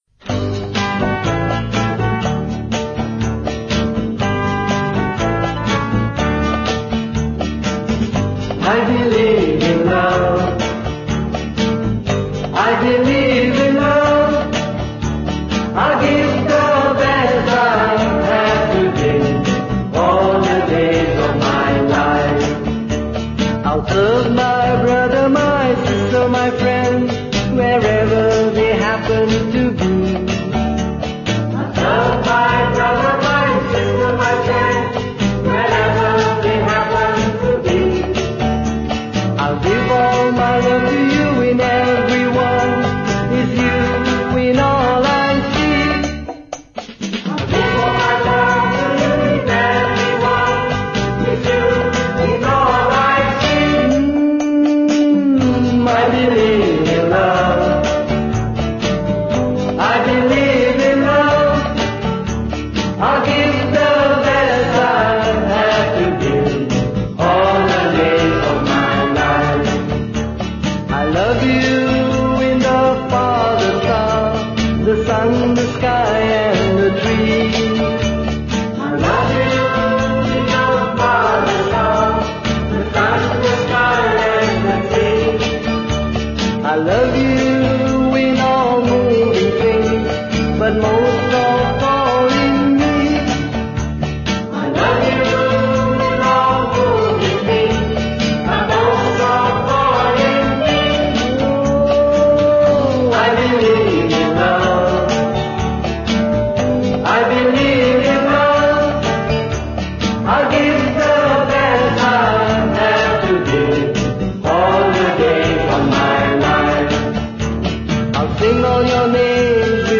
1. Devotional Songs
~ Major (Shankarabharanam / Bilawal)
8 Beat / Keherwa / Adi
Medium Fast